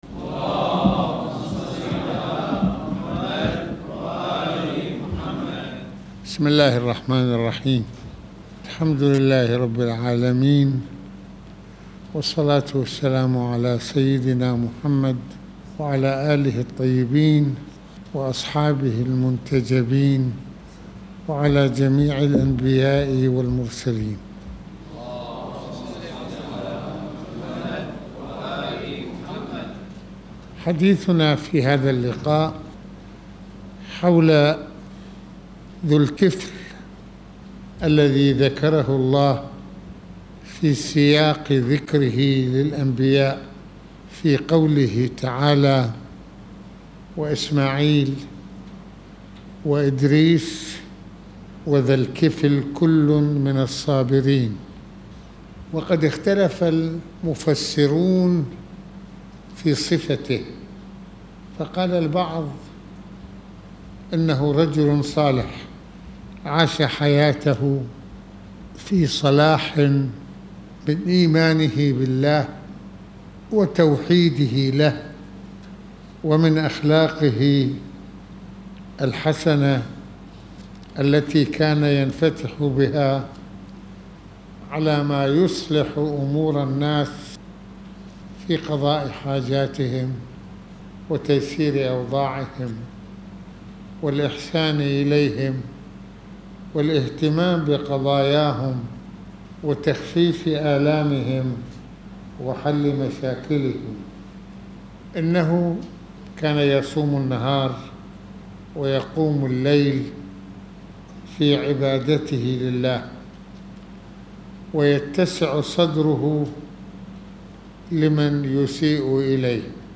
المناسبة : ندوة السبت المكان : الشام - السيدة زينب(ع)